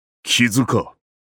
刀剑乱舞_Nenekirimaru-minorinjury1.mp3